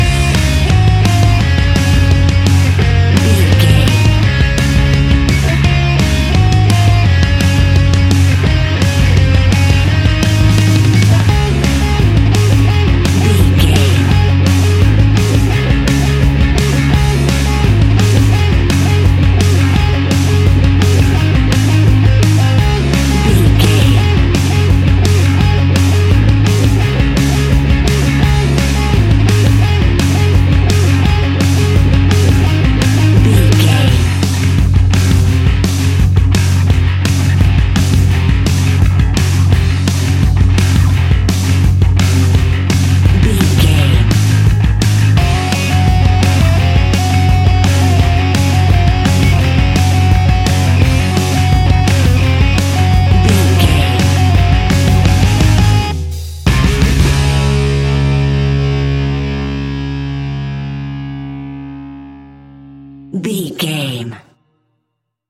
Uplifting
Ionian/Major
D♭
guitars
hard rock
distortion
punk metal
instrumentals
Rock Bass
Rock Drums
heavy drums
distorted guitars
hammond organ